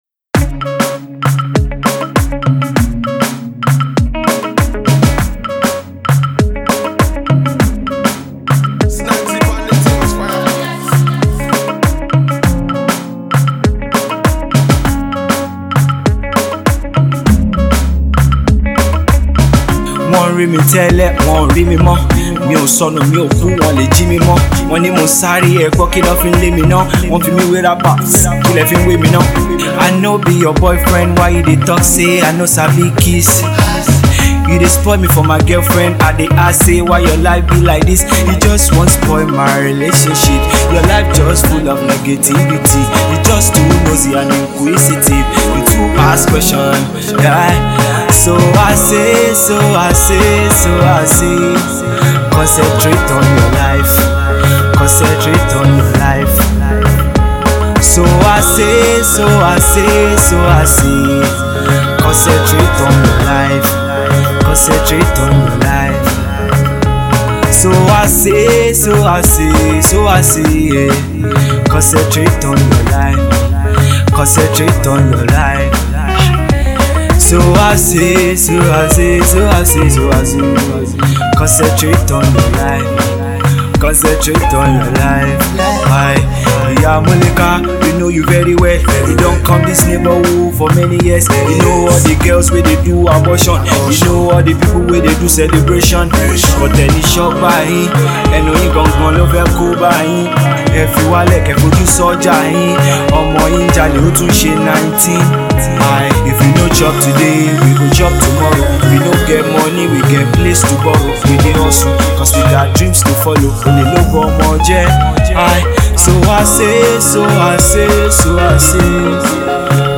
an Afro pop/high life fusion of sweet melodious beats .